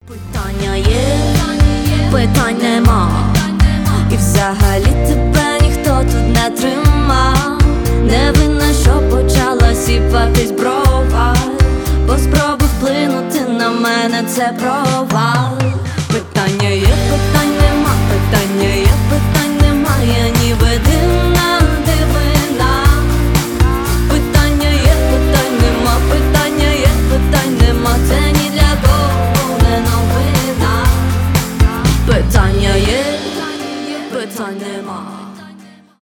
2025 » Новинки » Украинские » Поп Скачать припев